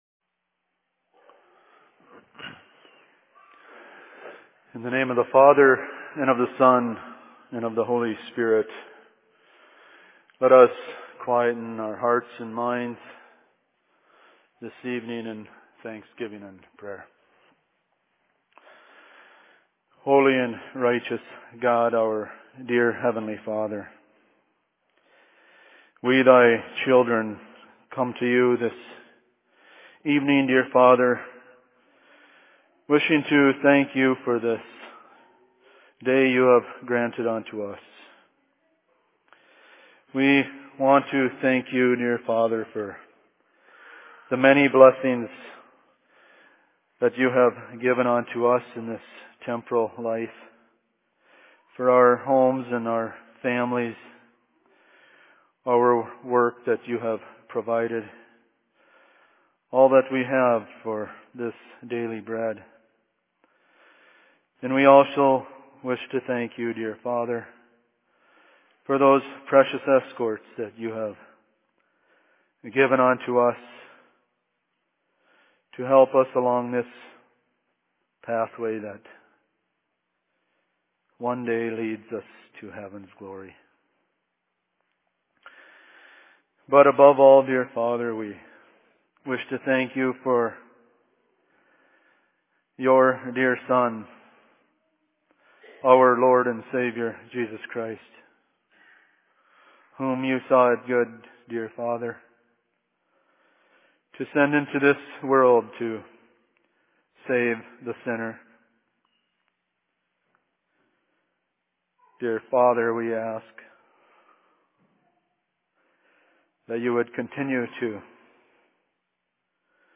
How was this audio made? Sermon in Cokato 19.05.2013